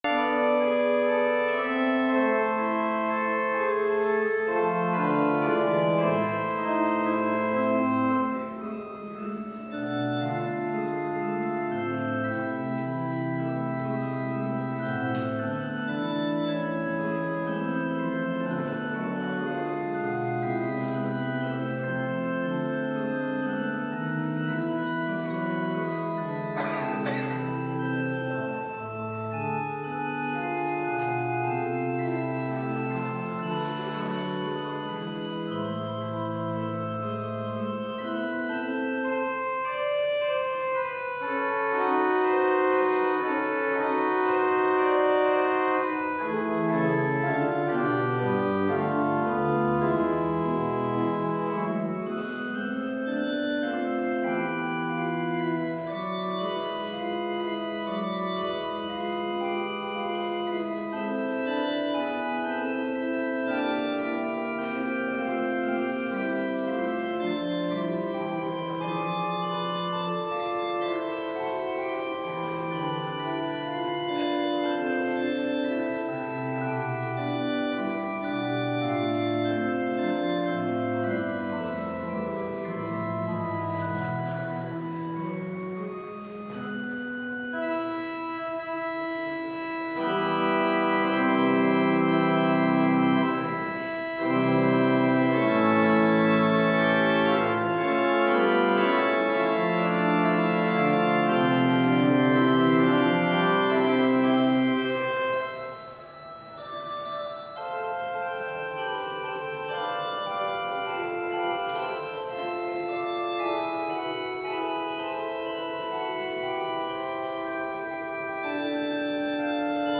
Complete service audio for Chapel - January 23, 2020